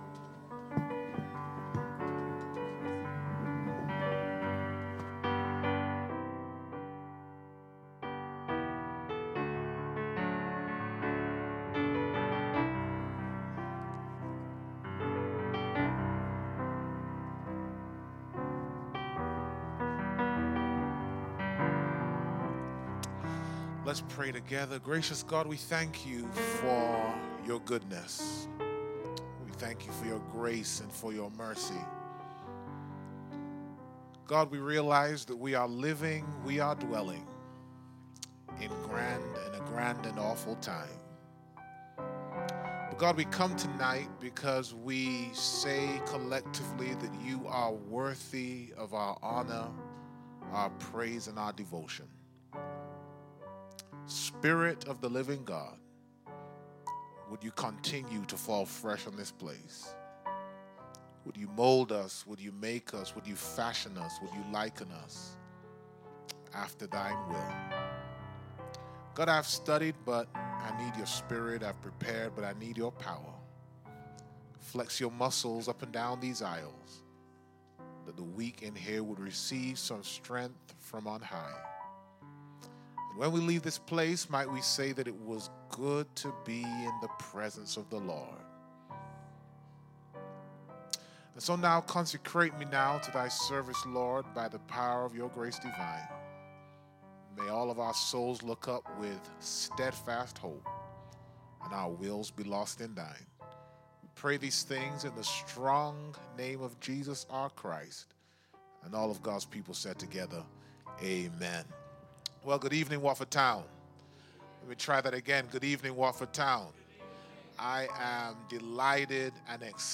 Sunday Night presentation from the SEC Camp Meeting 2024